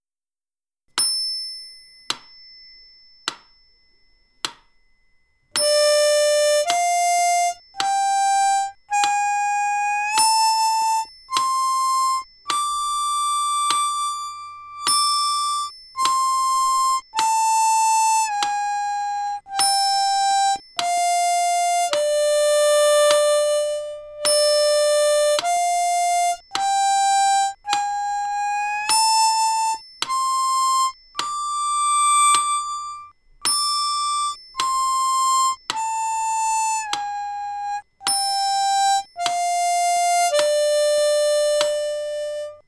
Ниже представлен аудио-пример, записанный на скорости 50 уд/мин. 1-й удар выделен «звоночком».
03bluesscalemetronome.mp3